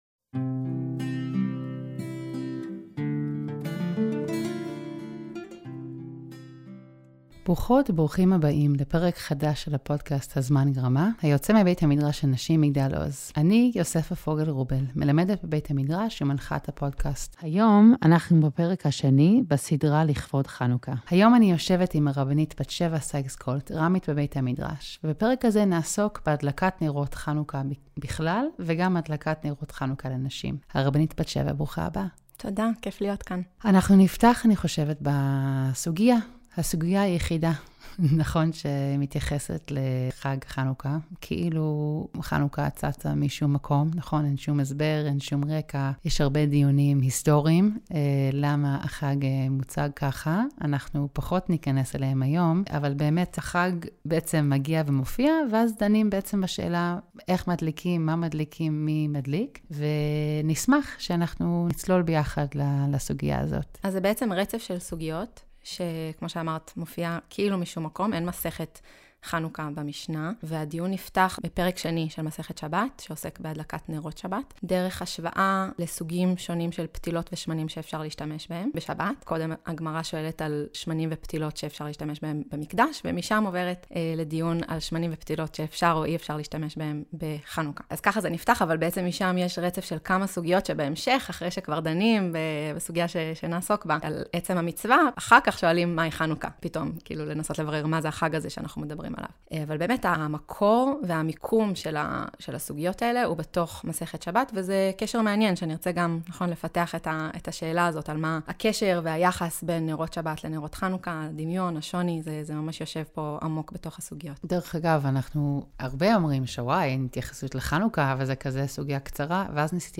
משוחחת